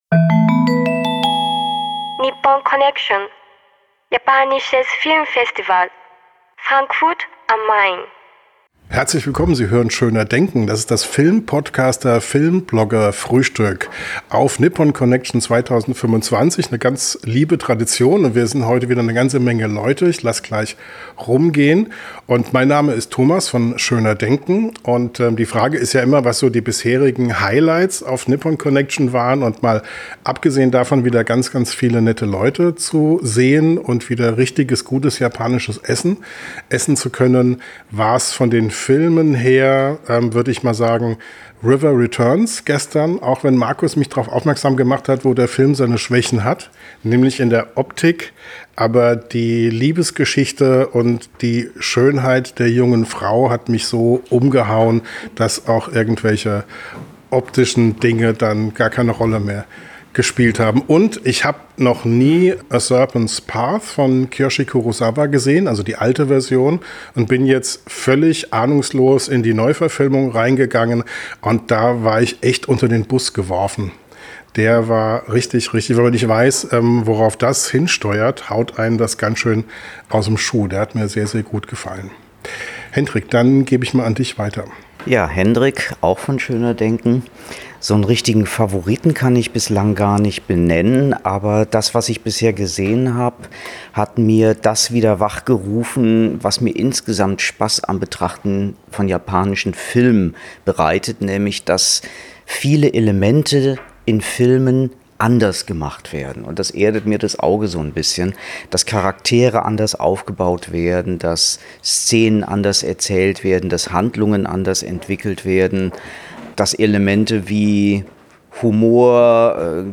Jedes Jahr lassen wir dabei einmal das Aufnahmegerät rumgehen und jede/r erzählt von seinen Festivalhighlights.